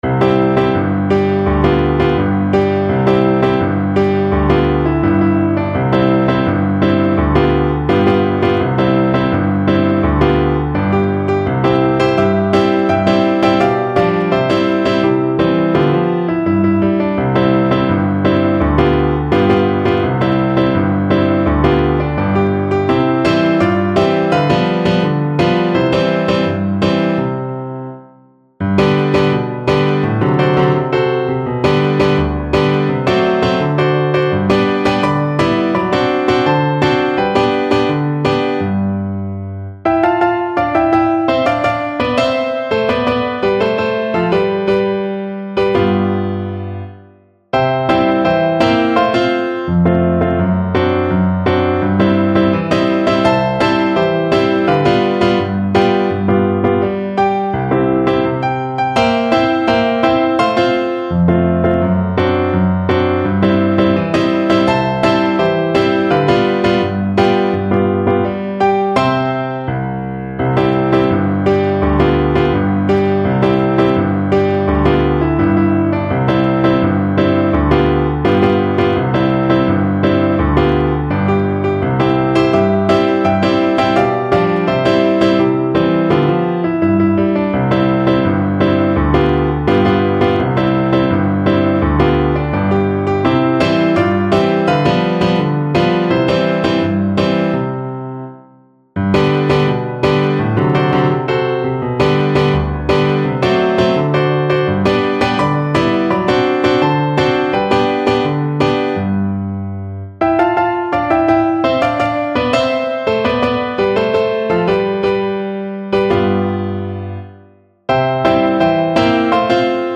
Violin
C major (Sounding Pitch) (View more C major Music for Violin )
2/4 (View more 2/4 Music)
World (View more World Violin Music)
Brazilian Choro for Violin
menina_faceira_VLN_kar1.mp3